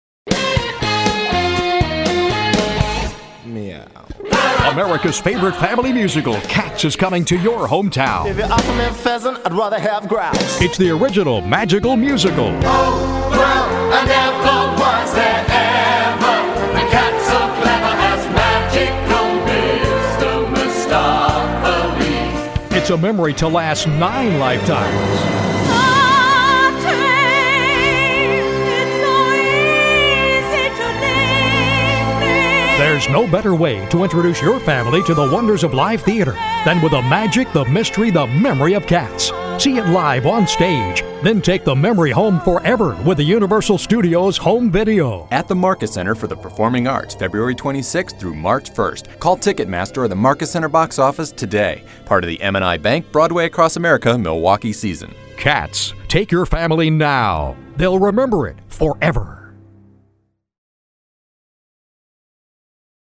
Cats Radio Commercial